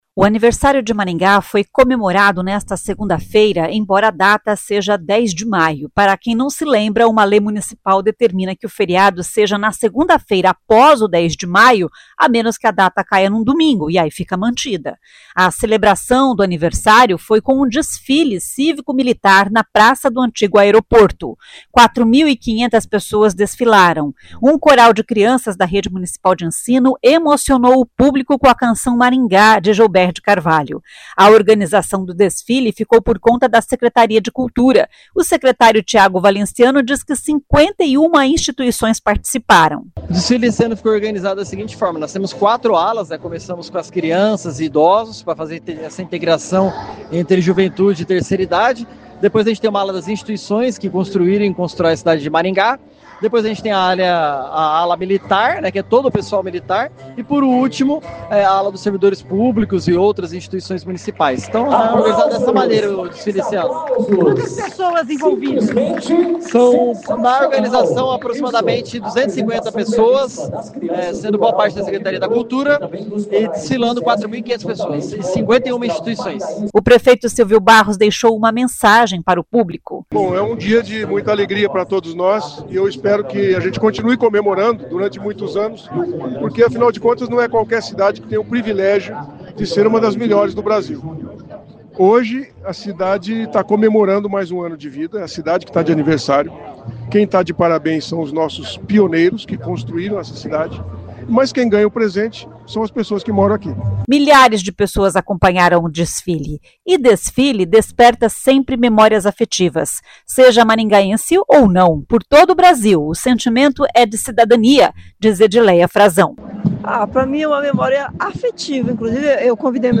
O secretário Tiago Valenciano diz que 51 instituições participaram.
O prefeito Silvio Barros deixou uma mensagem para o público.